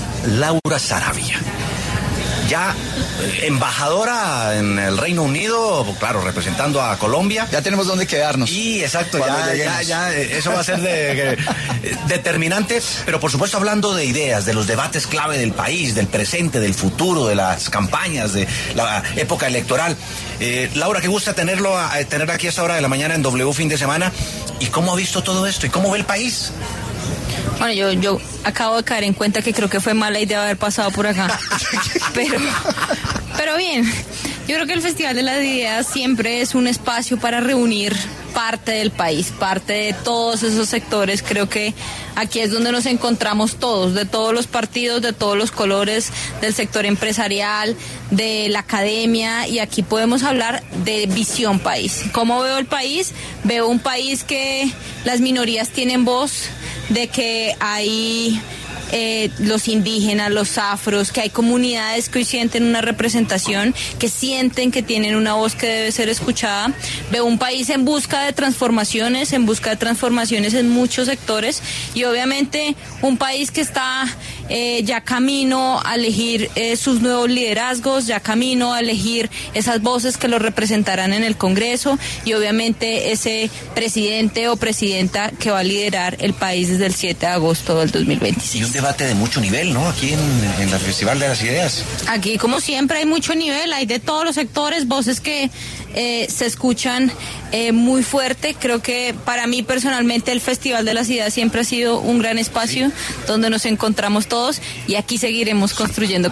Laura Sarabia, embajadora en Reino Unido, pasó por los micrófonos de W Fin De Semana para hablar sobre el Festival de las Ideas y cómo avanza el país a pocos meses para la elección de un nuevo presidente de la República.